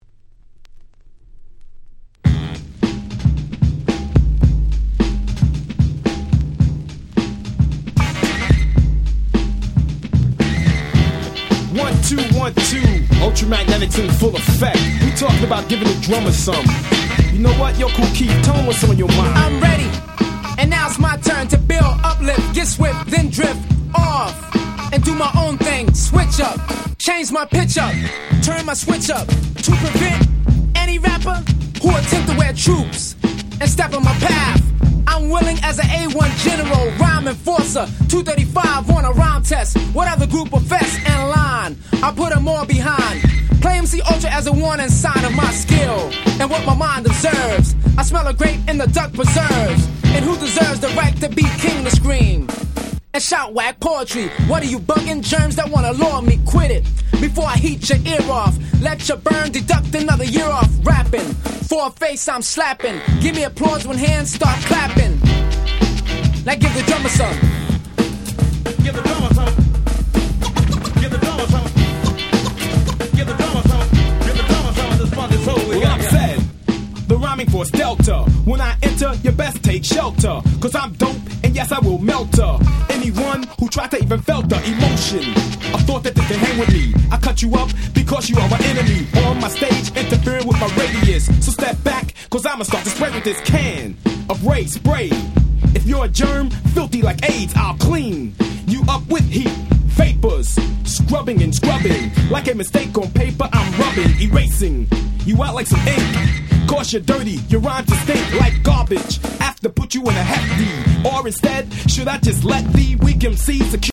89' Super Hip Hop Classics !!
80's Middle School ミドルスクール